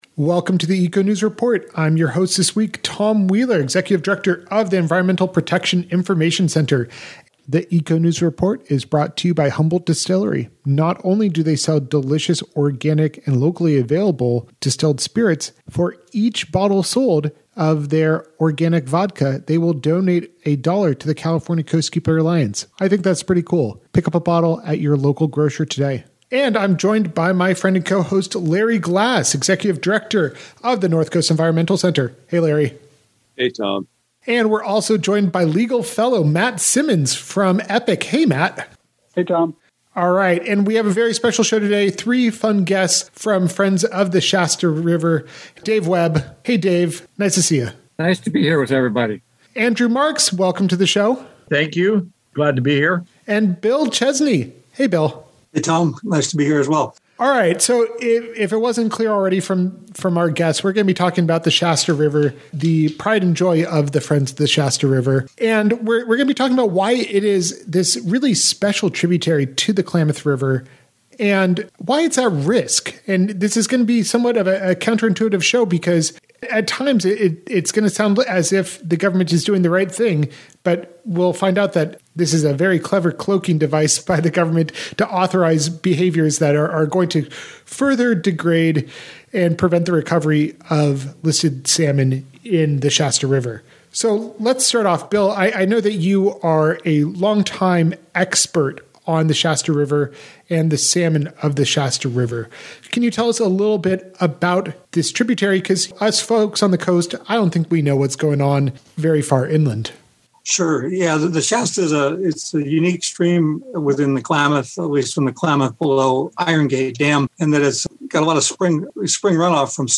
EcoNews Report Radio Show interview (audio).